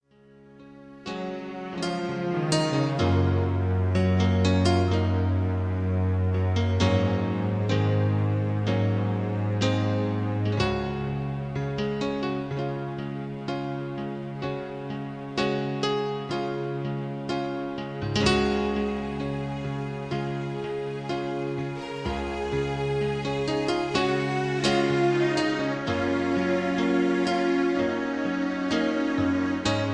Karaoke MP3 Backing Tracks
mp3 backing tracks